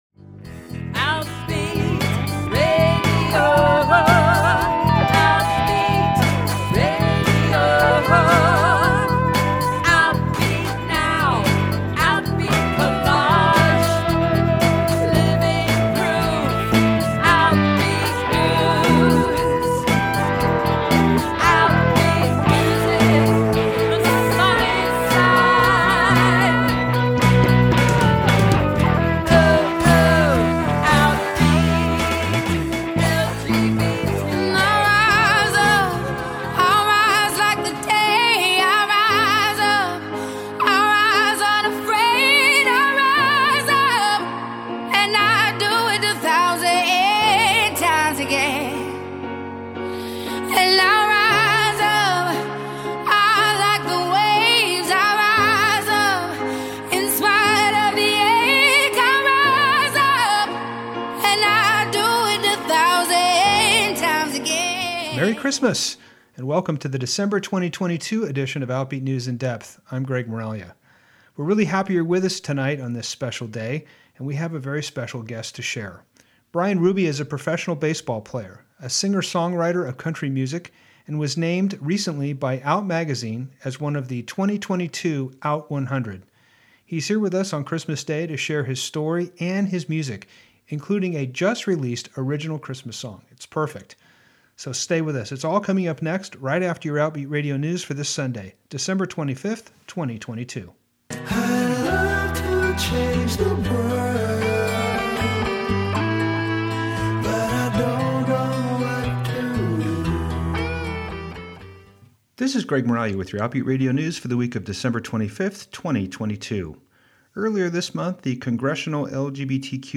He shares his story and his music including some new Christmas music released this month.